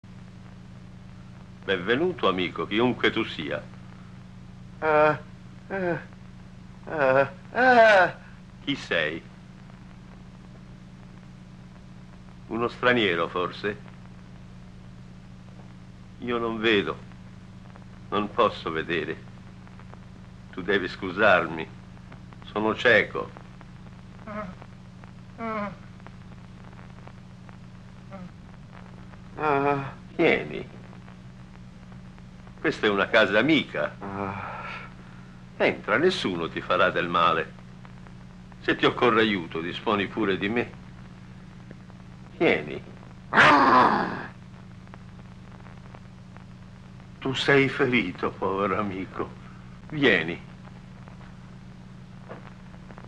voce di Gero Zambuto nel film "La moglie di Frankenstein", in cui doppia O.P. Heggie.
• O.P. Heggie in "La moglie di Frankenstein" (Eremita cieco)